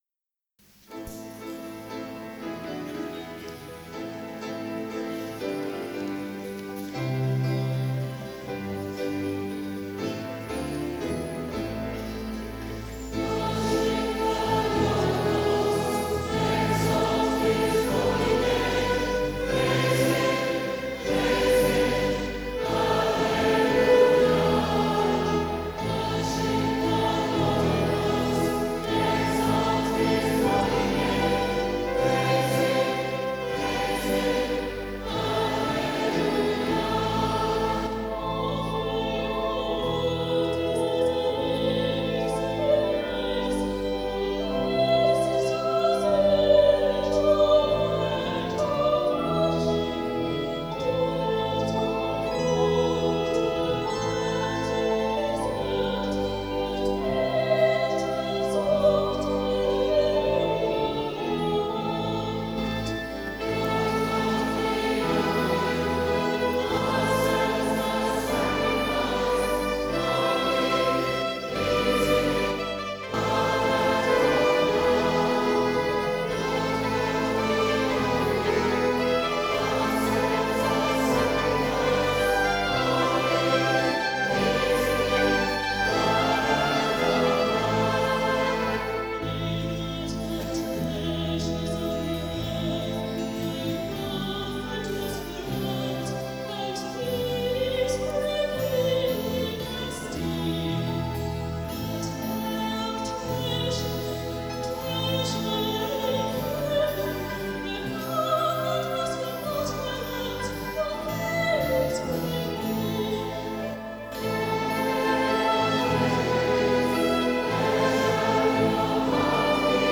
LAUDATE DOMINUM – [Taize Chant]